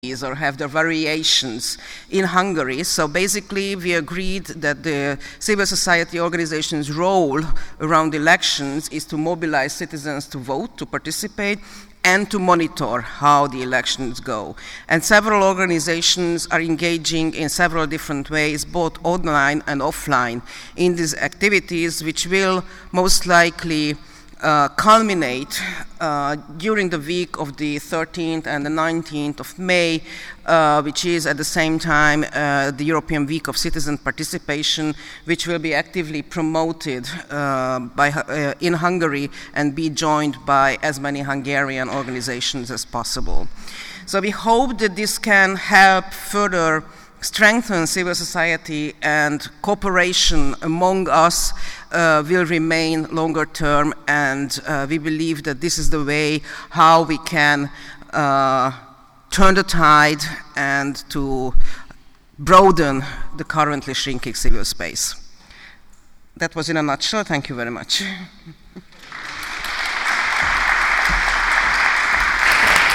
Die vorliegende Ausgabe der BBE Europa-Nachrichten dient als eine Dokumentation der Konferenz der Zivilgesellschaft zur Zukunft Europas »Nicht ohne Uns!«, die am 21. und 22. März 2019 im Roten Rathaus Berlin stattgefunden hat.
Reden, kurzgefasste Statements und Diskussionen werden sowohl als Texte als auch als Audioaufzeichnungen in der Reihenfolge des Veranstaltungsprogramms zur Verfügung gestellt.